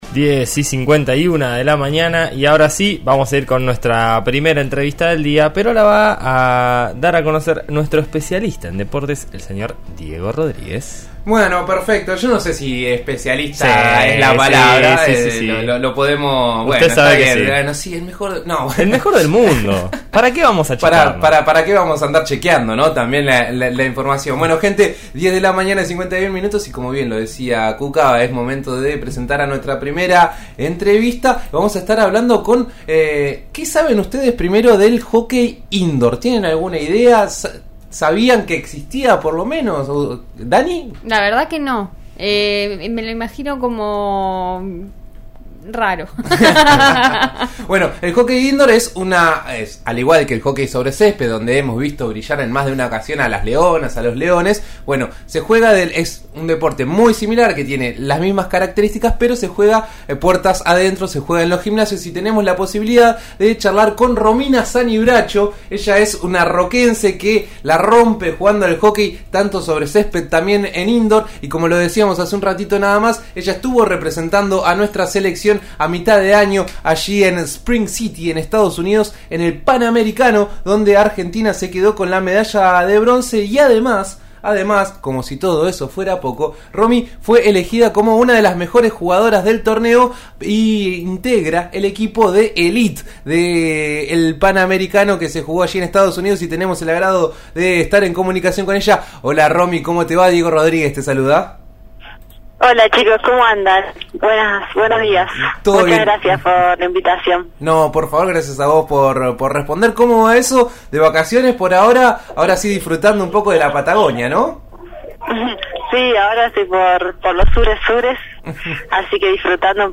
La roquense dialogó con En eso estamos de RN Radio (89.3) sobre el premio y además hizo un repaso por su gran carrera en la disciplina.